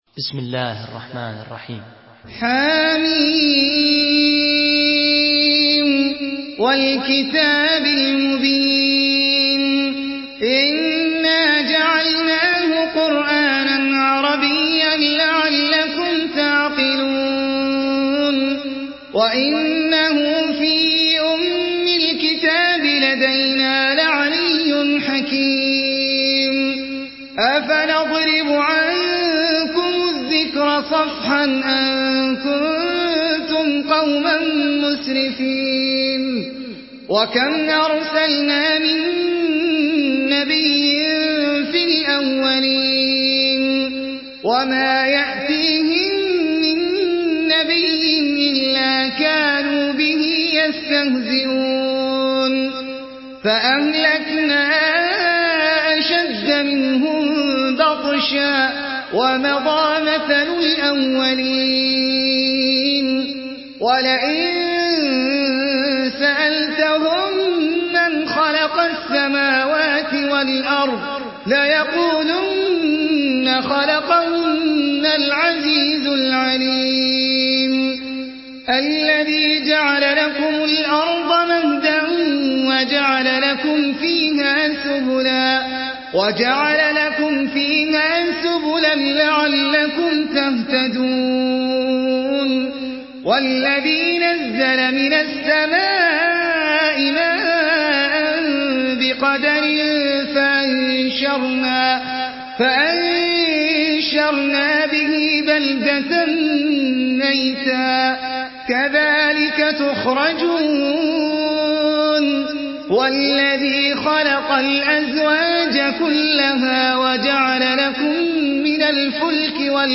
Surah আয-যুখরুফ MP3 by Ahmed Al Ajmi in Hafs An Asim narration.
Murattal Hafs An Asim